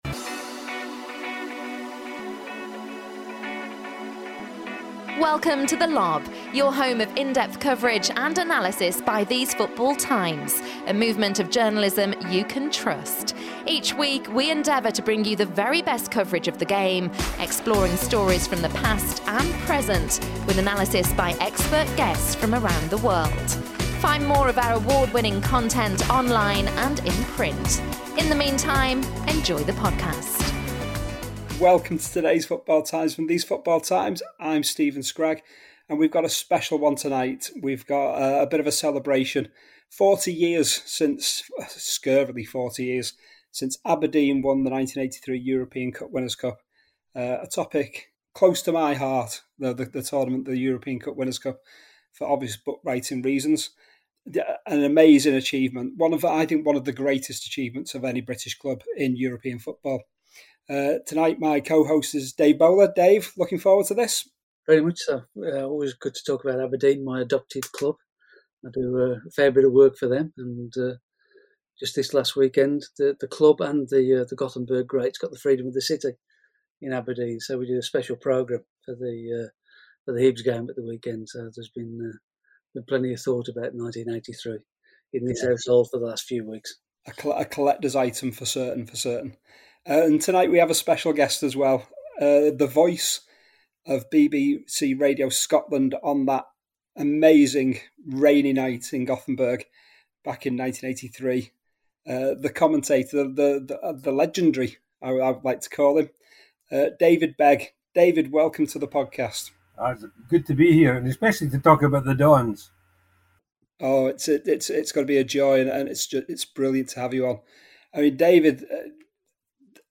The team are joined by David Begg, the doyen of radio commentators across his long and successful career with BBC Radio Scotland. We reminisce about covering Aberdeen’s Cup Winners' Cup final success over Real Madrid in 1983, Scotland’s trip to the World Cup in 1982, and "Glor...